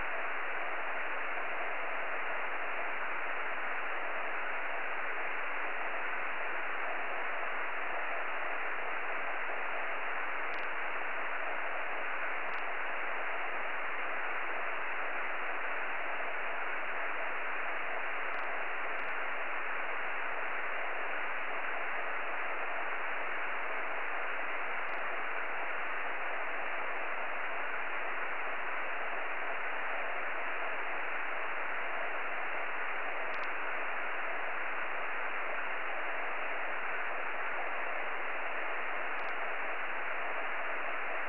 ５GHzのﾋﾞｰｺﾝ信号
信号音（録音）
2007_7_10　朝　2007_7_12　朝　ともに　三重県上空に強い雨雲、ﾚｲﾝｽｷｬｯﾀによる入感